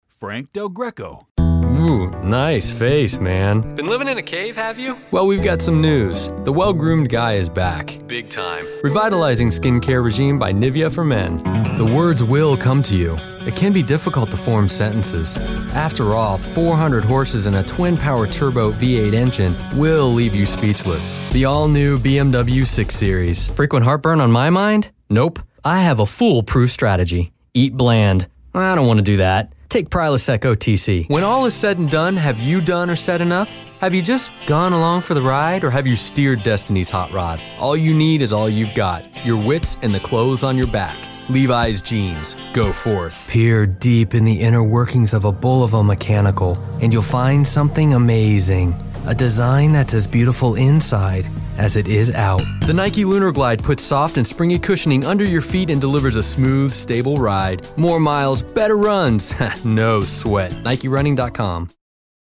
Commercial
English - USA and Canada
Middle Aged